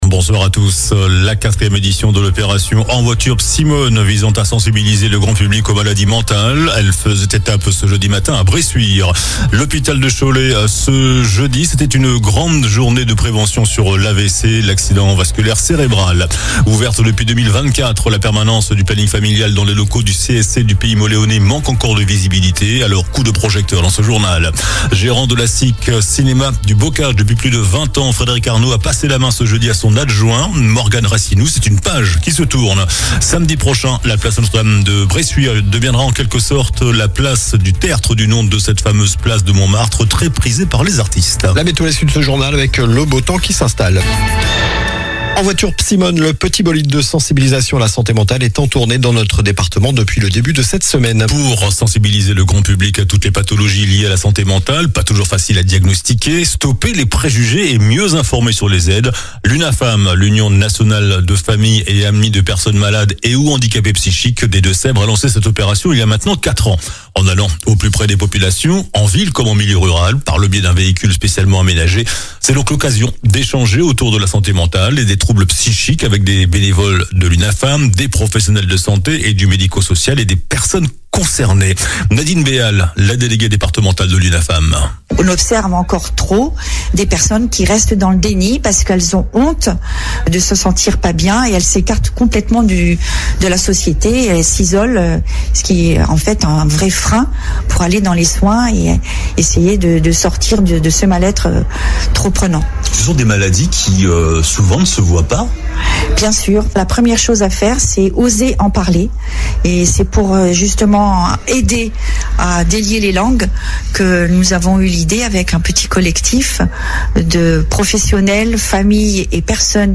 JOURNAL DU JEUDI 15 MAI ( SOIR )